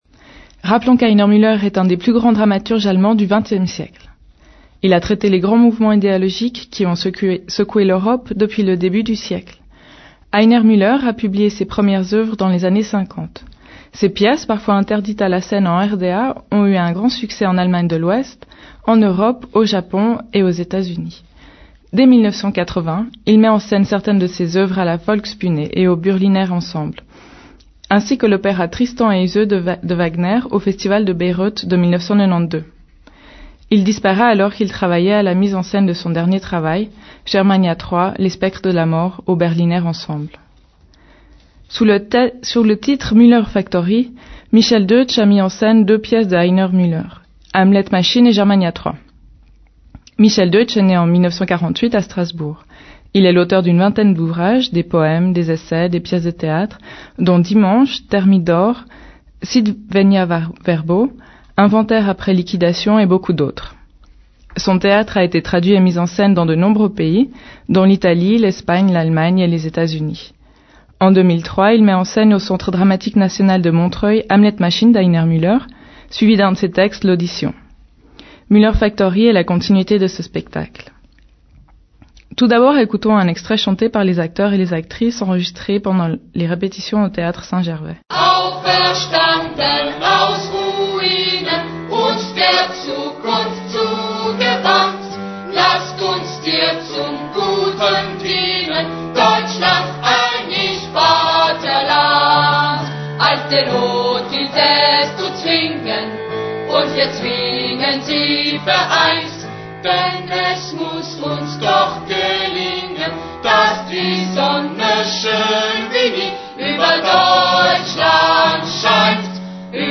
Michel Deutsch, interview, 28 janvier 2005.